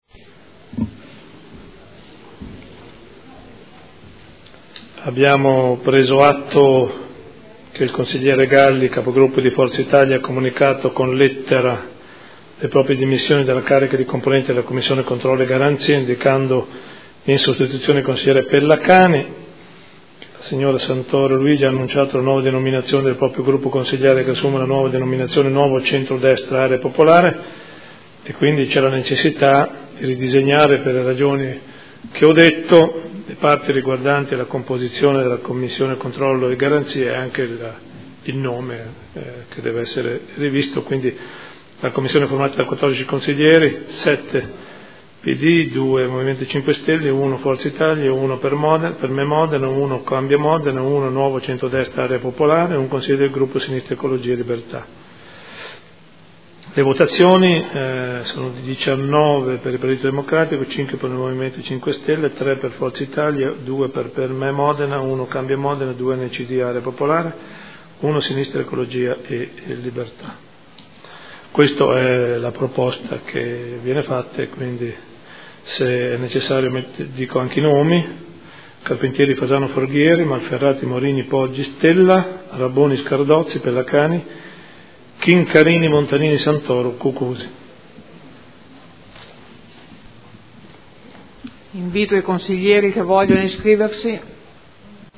Sindaco — Sito Audio Consiglio Comunale